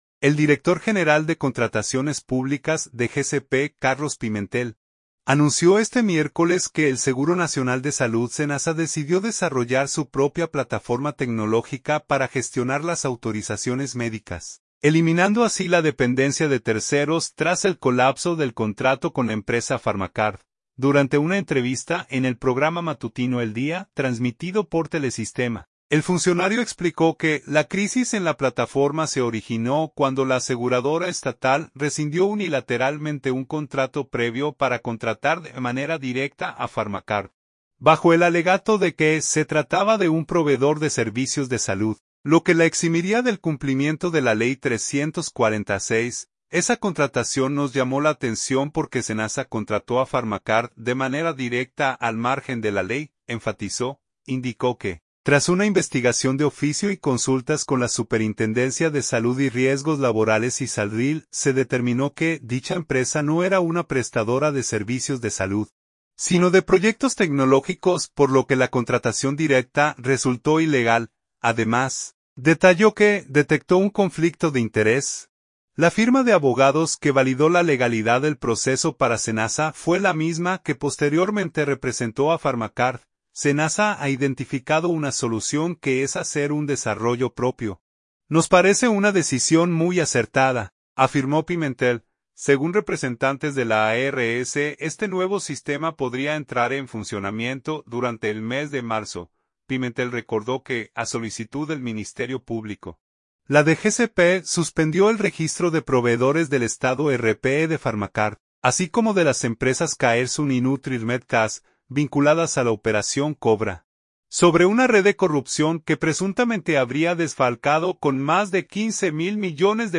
Durante una entrevista en el programa matutino El Día, transmitido por Telesistema, el funcionario explicó que la crisis en la plataforma se originó cuando la aseguradora estatal rescindió unilateralmente un contrato previo para contratar de manera directa a Farmacard, bajo el alegato de que se trataba de un “proveedor de servicios de salud”, lo que la eximiría del cumplimiento de la Ley 340-06.